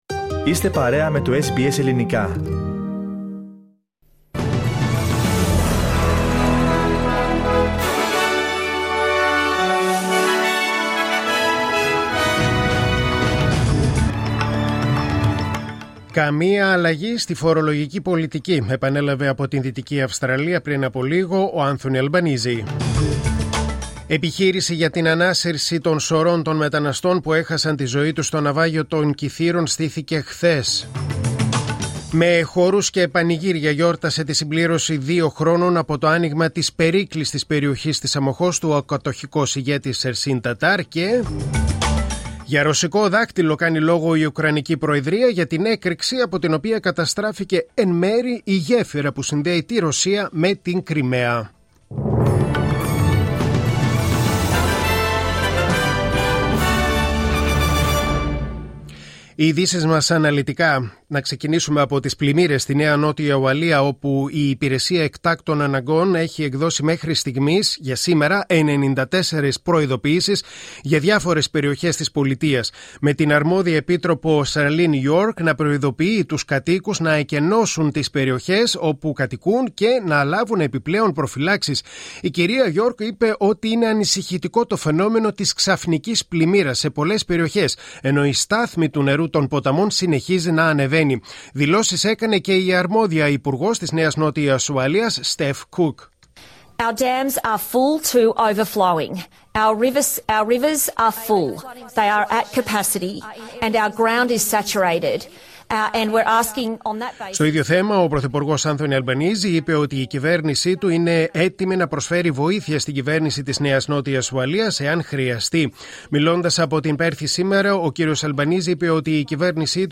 Δελτίο Ειδήσεων: Κυριακή 9.10.2022